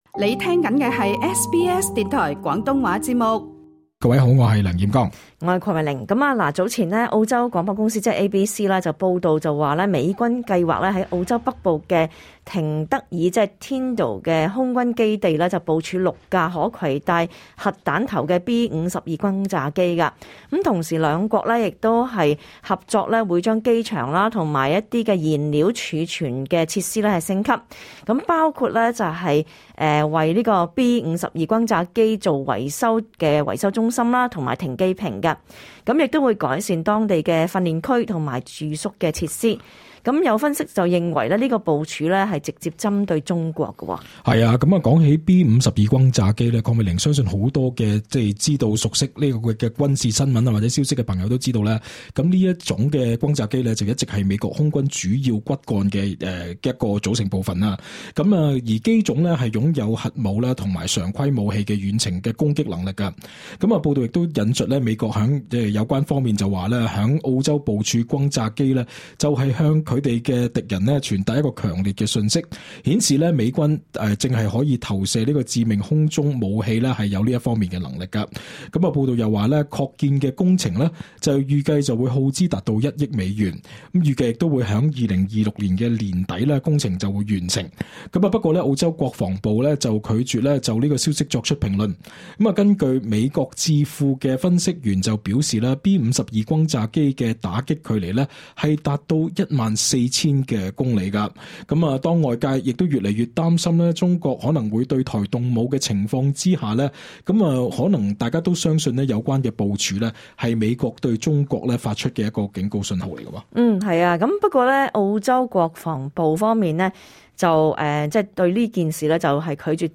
*本節目內嘉賓及聽眾意見並不代表本台立場 READ MORE 【那個國家最嚴重？